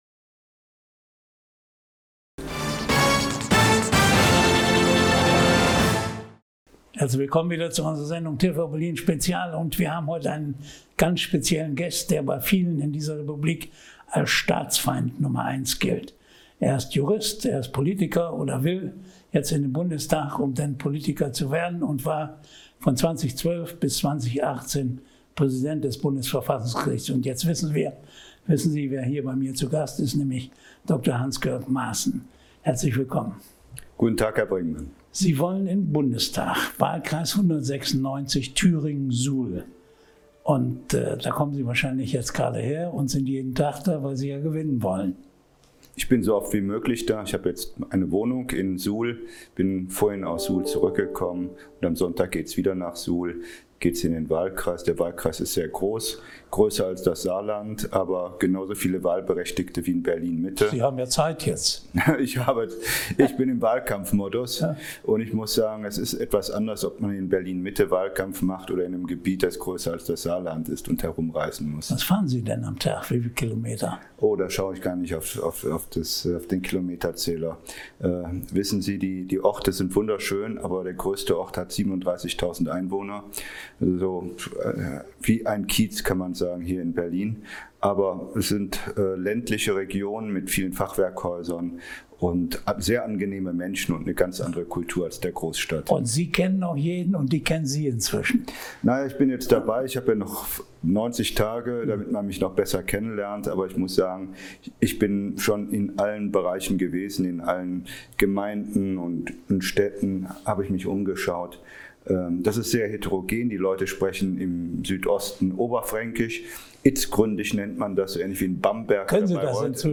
Teil 1 des absolut hörenswerten Interview.